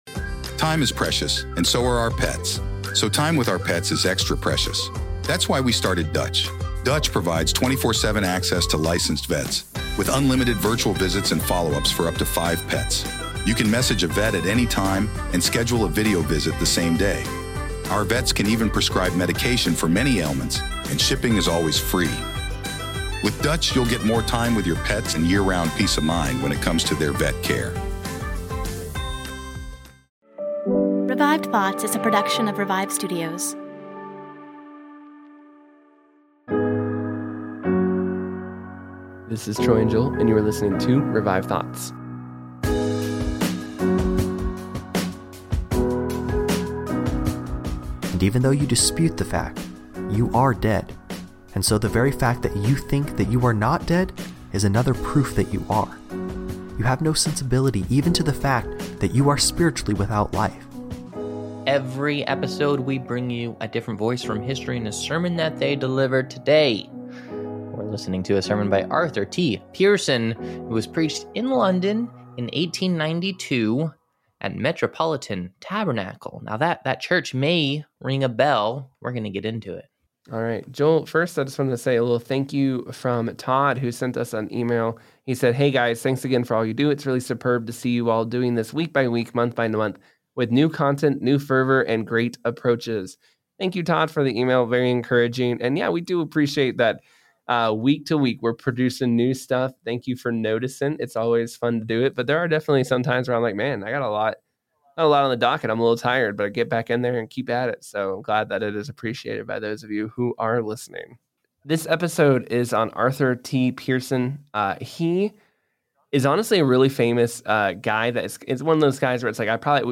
Arthur T. Piersen was a world famous evangelist who filled the pulpit for Charles Spurgeon after his death. His heart for missions and preaching the Gospel to the lost can be seen in this sermon.